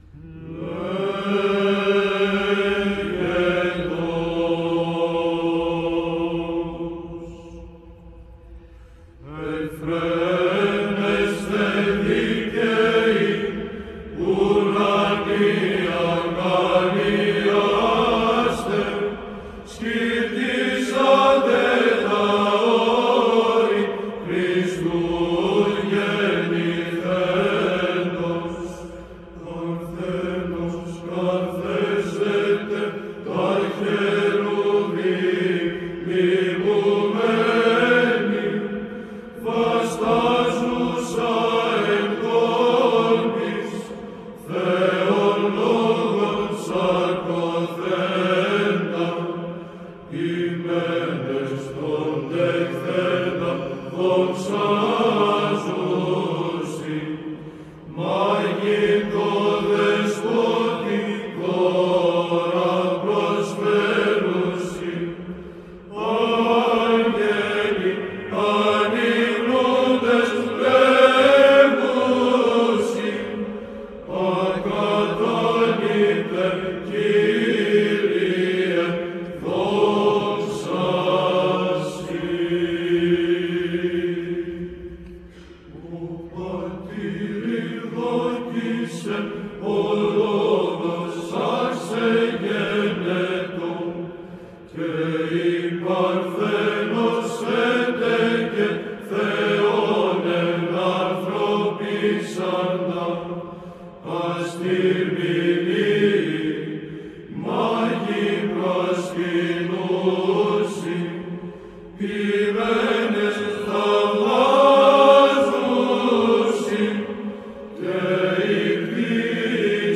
ΒΥΖΑΝΤΙΝΗ ΧΟΡΩΔΙΑ ΕΡΕΥΝΗΤΙΚΟΥ ΩΔΕΙΟΥ ΧΑΛΚΙΔΟΣ
Ψάλλει η Β.Χ.Ε.Ω. Χαλκίδος
ΤΙΤΛΟΣ:  Ευφραίνεσθε Δίκαιοι... Στιχηρό Ιδιόμελο των Αίνων.
ΗΧΟΣ:   Τέταρτος.